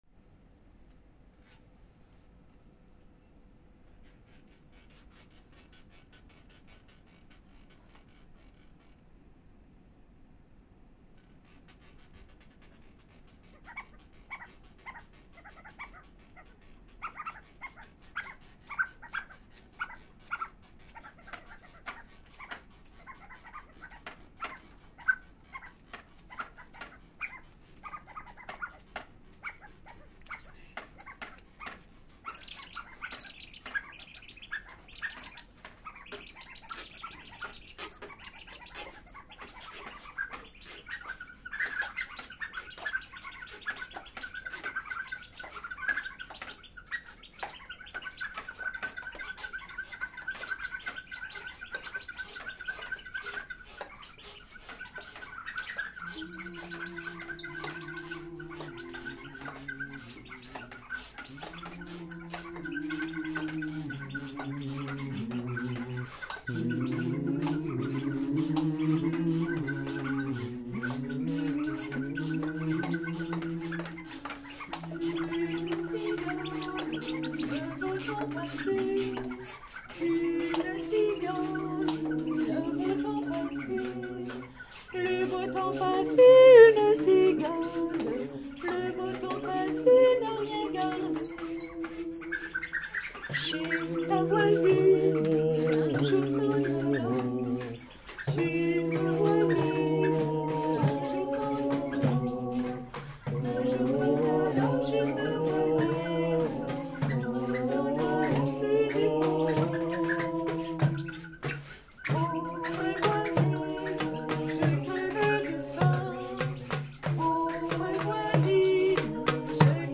Travail de recherche autour de la pratique vocale.